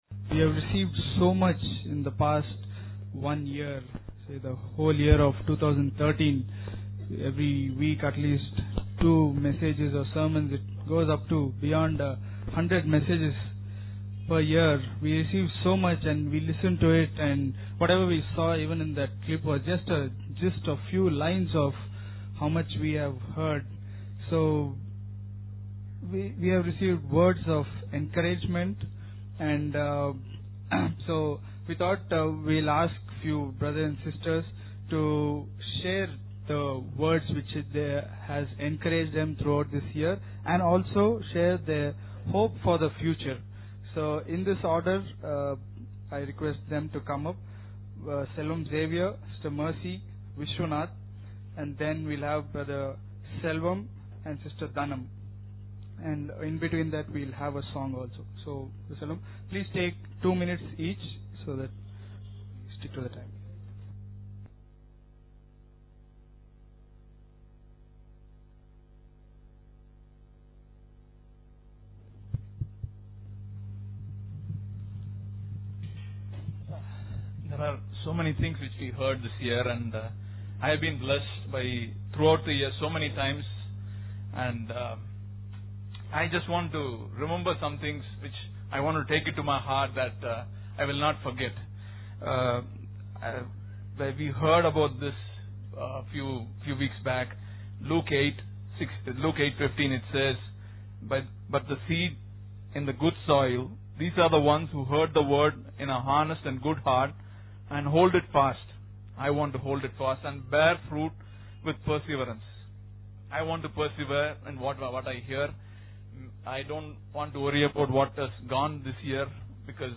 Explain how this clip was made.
new-year-eve-service.mp3